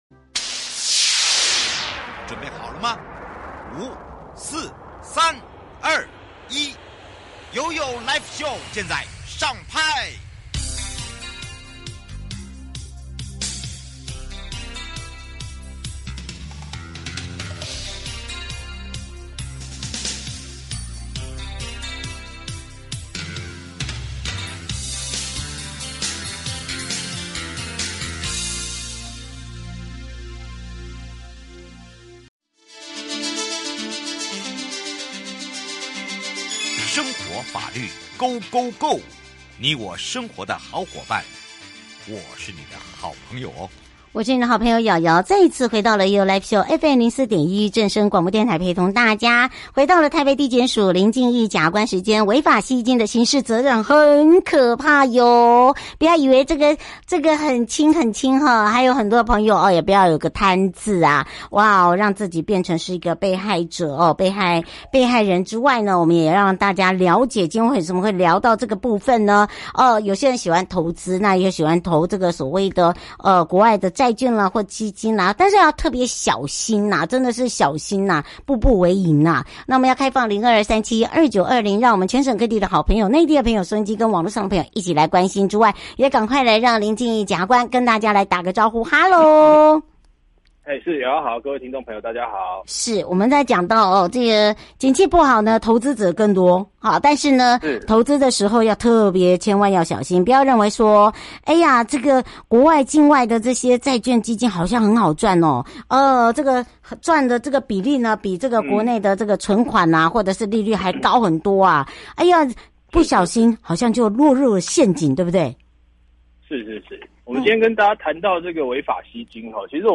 受訪者： 臺北地檢署林晉毅檢察官 節目內容： 違法吸金的刑事責任 引言：兆富財務管理顧問公司因未經許可，違法在